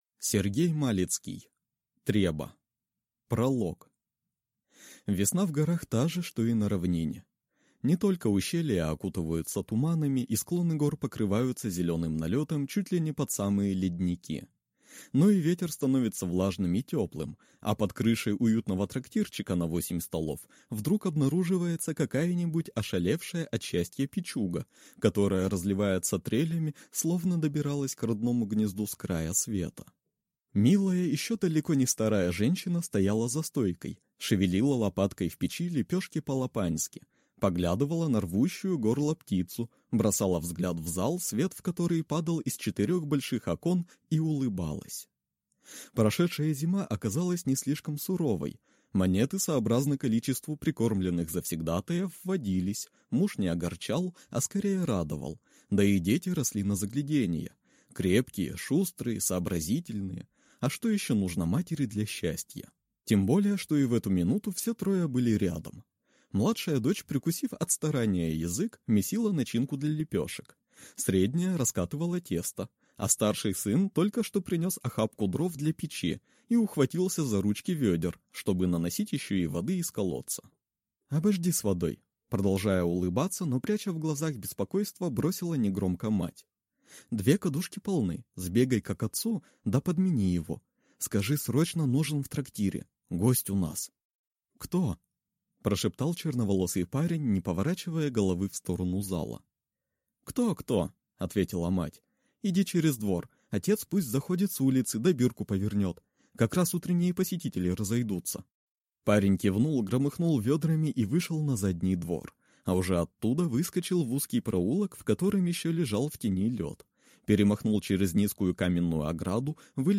Аудиокнига Треба | Библиотека аудиокниг